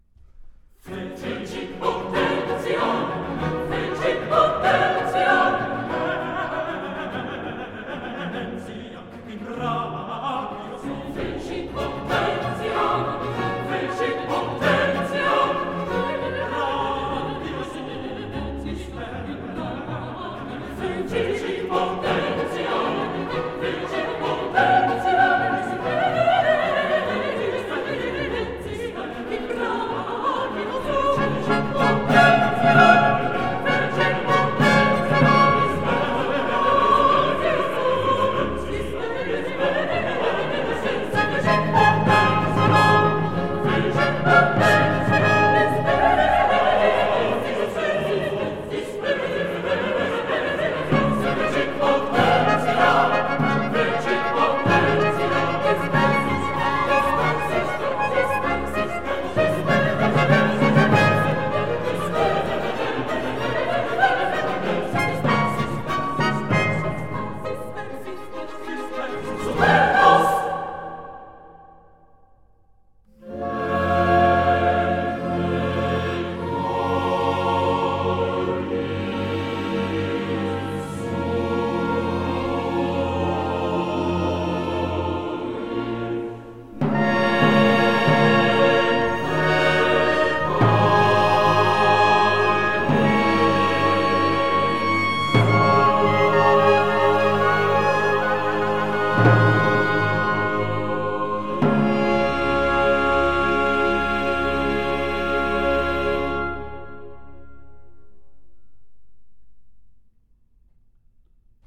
Fecit potentiam: Chorus